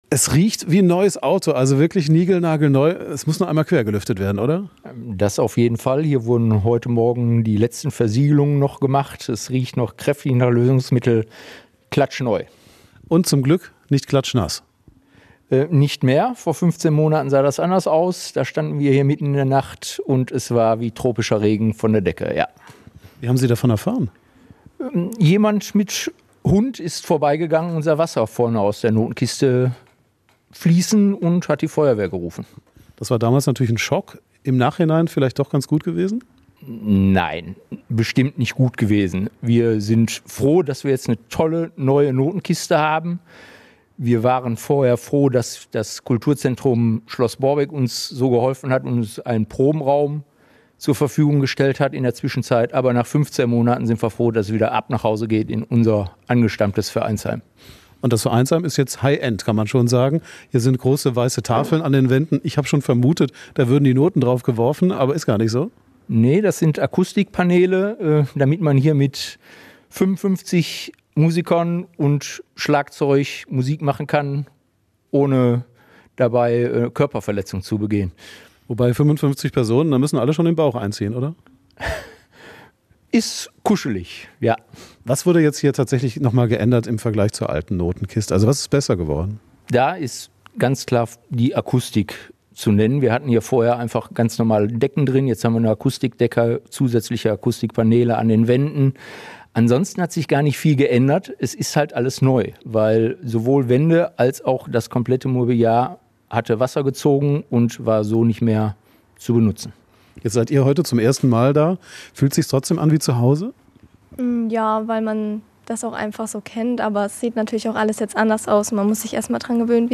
Wir waren zum Soundcheck dort.
Für Radio Essen gab es einen ersten Soundcheck in den neuen alten Räumen.